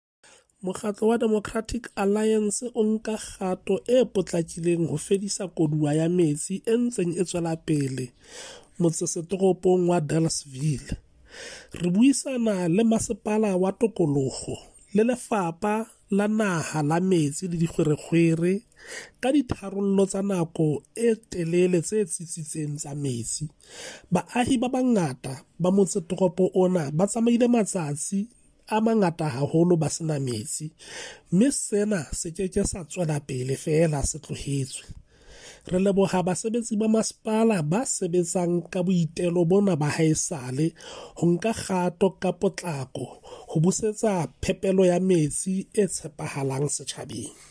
Sesotho soundbite by Cllr Hismajesty Maqhubu